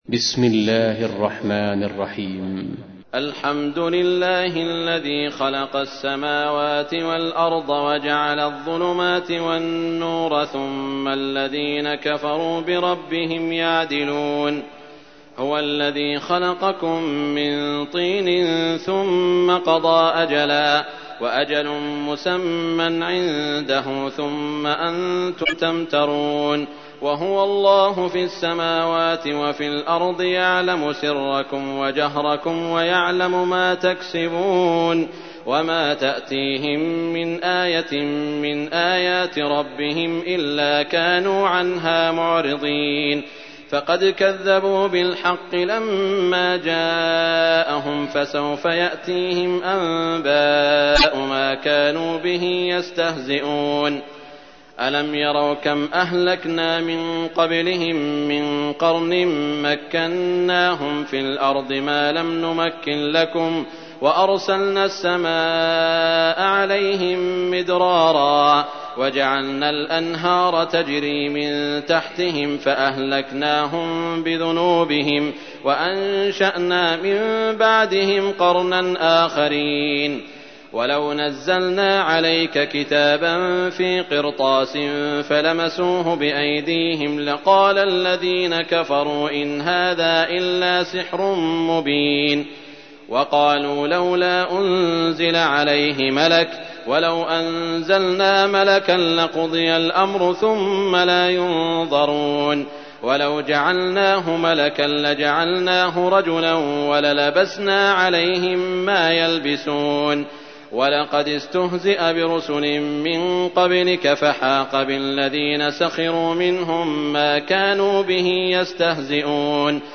تحميل : 6. سورة الأنعام / القارئ سعود الشريم / القرآن الكريم / موقع يا حسين